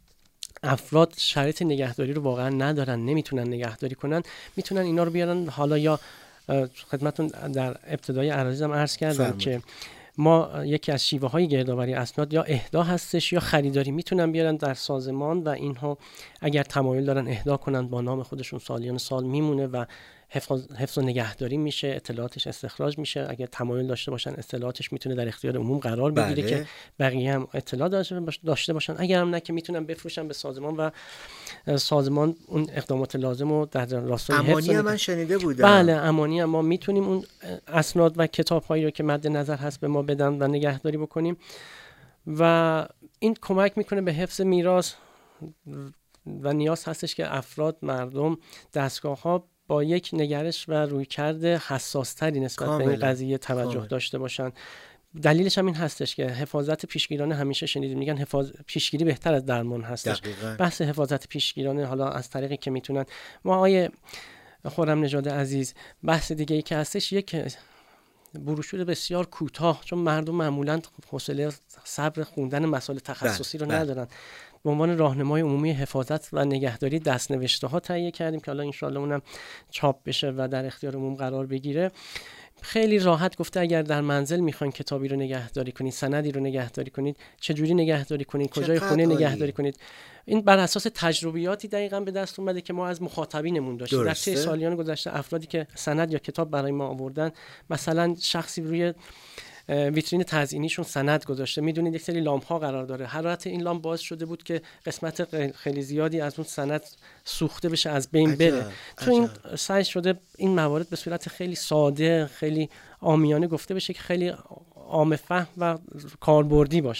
میزگرد ایکنا به مناسبت روز اسناد ملی و میراث مکتوب/ 2